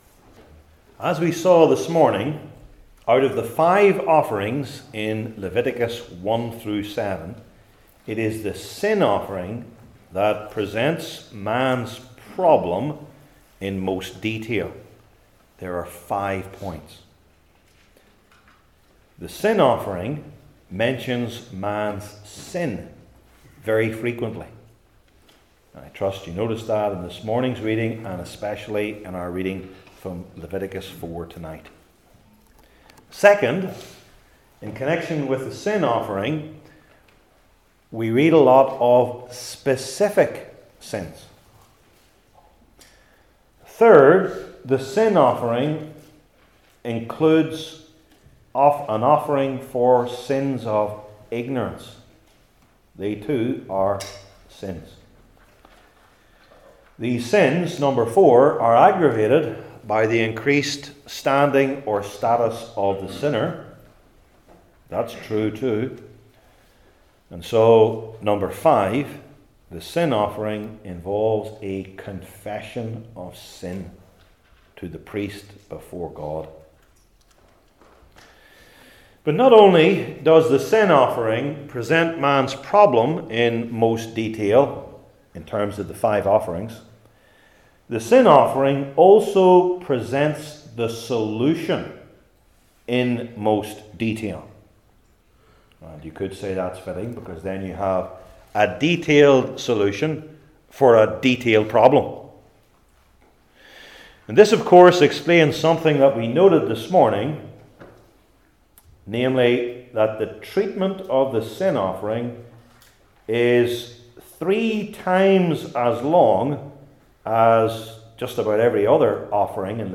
Leviticus 4:1-21 Service Type: Old Testament Sermon Series I. Two Distinctive Features II.